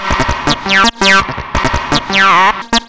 07_Teacakes_165_G_.wav